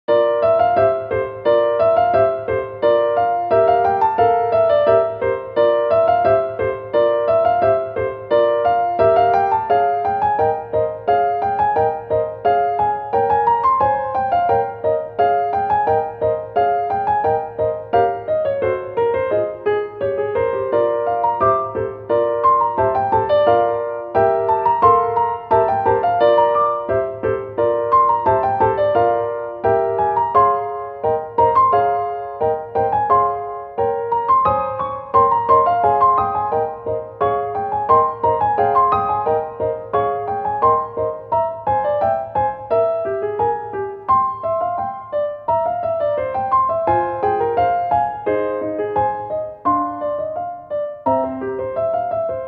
ogg(L) - しっとり 暗い 孤独